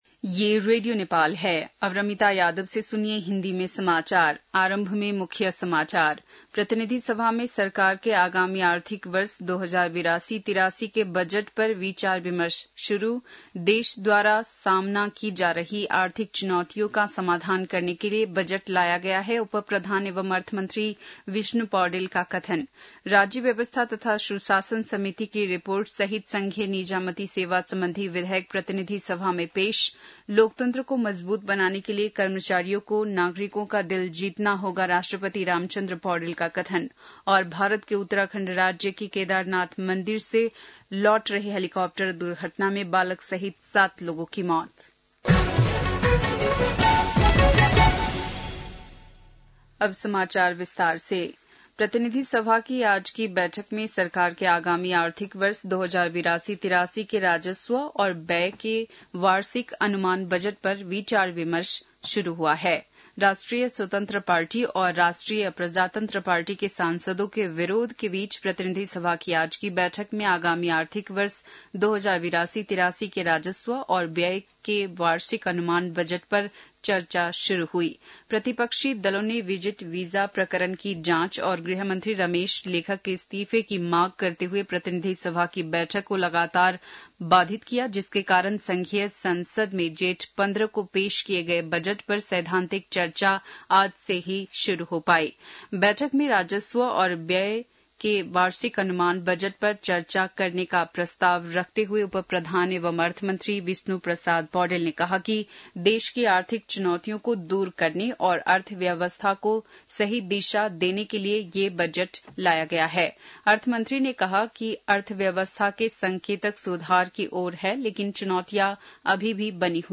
बेलुकी १० बजेको हिन्दी समाचार : १ असार , २०८२
10-pm-hindi-news-.mp3